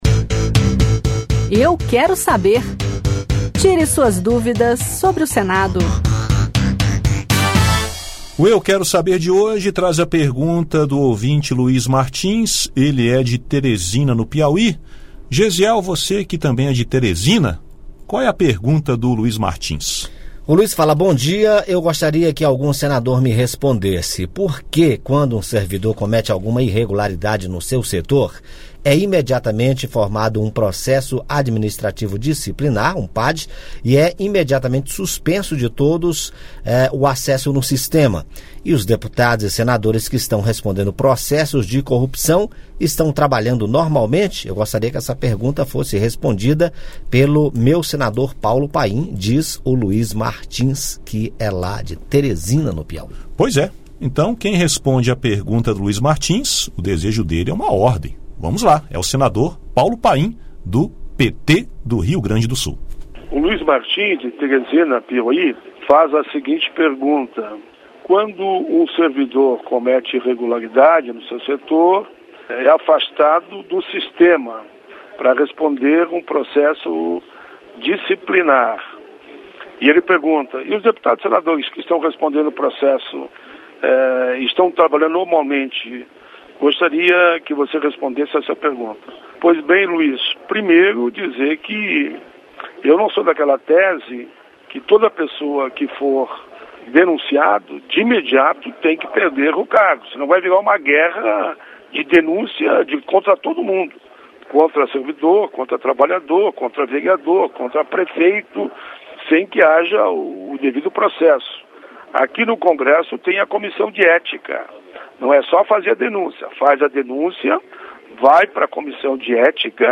Ele pergunta porque um servidor que comete alguma irregularidade sofre suspensão das atividades e um processo administrativo e quando um deputado ou senador envolvido em processo continua trabalhando normalmente. Quem responde é o senador Paulo Paim (PT-RS).